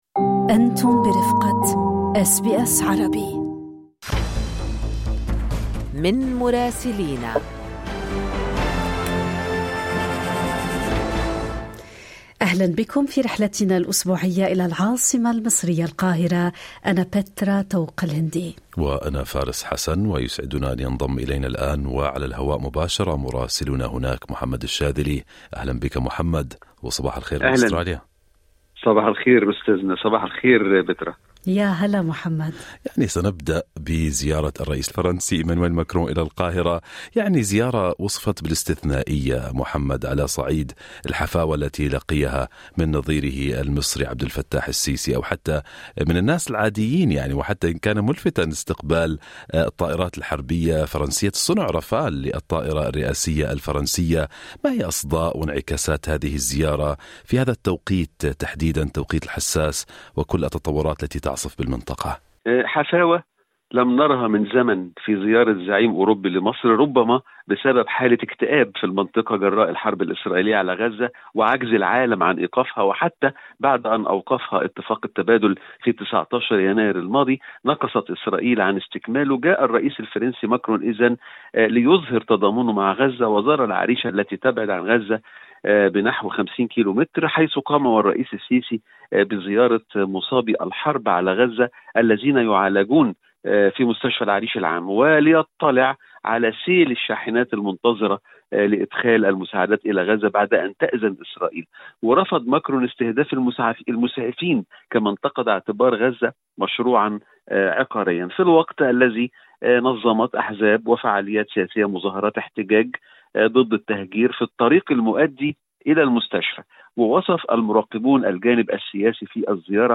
"شوربة عدس ومشويات": وجبة غداء السيسي وماكرون وموضوعات أخرى مع مراسلنا في القاهرة